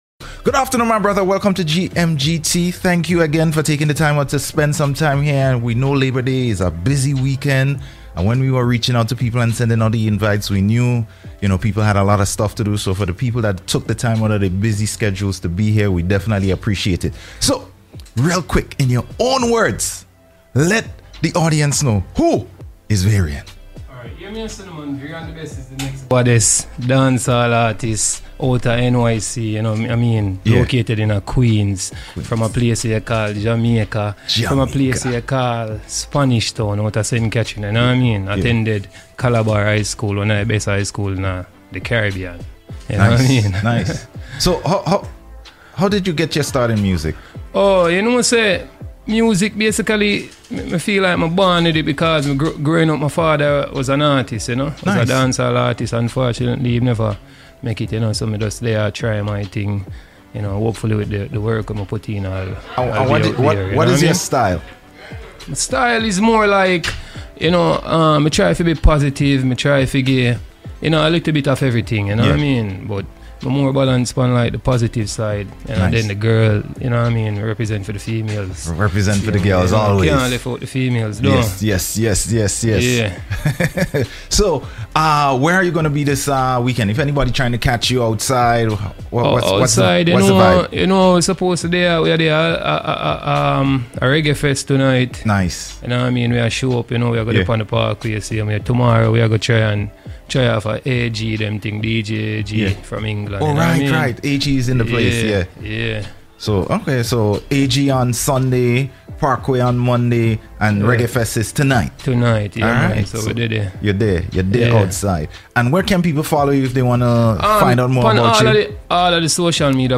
INTERVIEWS AND PERFORMANCES FROM A DIVERSE CAST OF CARIBBEAN ARTISTES